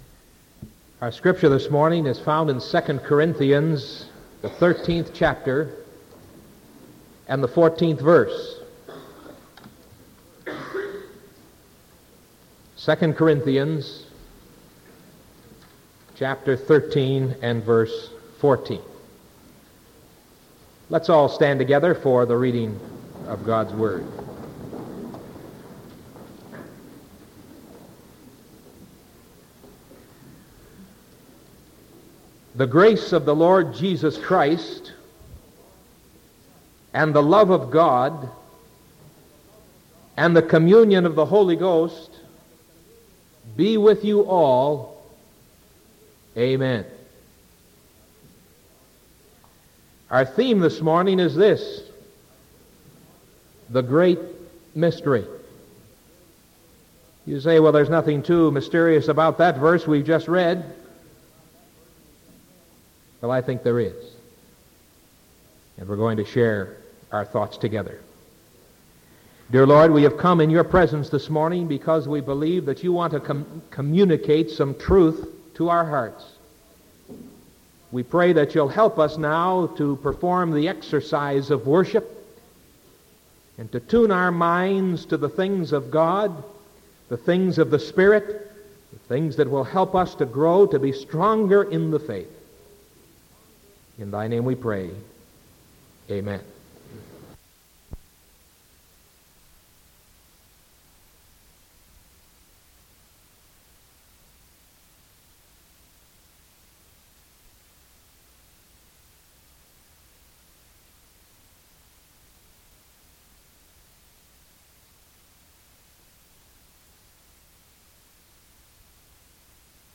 Sermon from October 12th 1975 AM